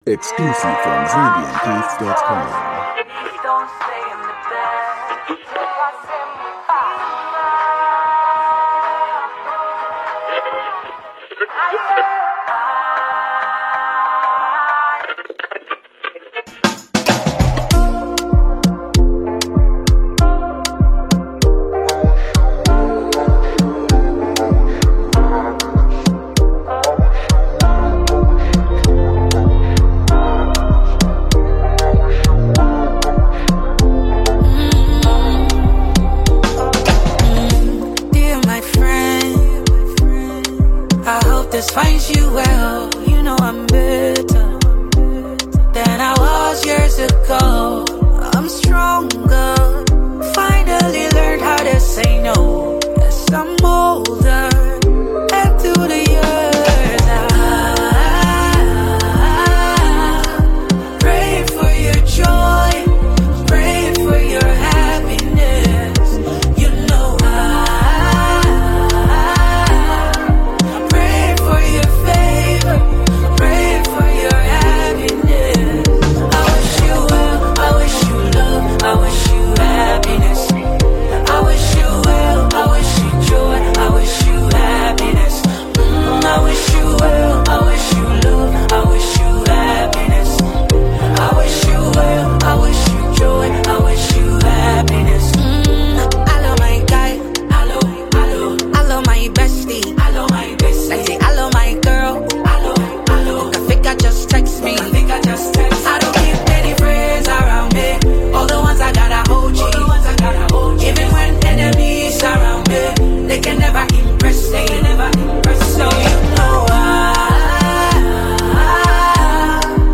Zambian gifted singer and songwriter
smooth vocals
soulful melodies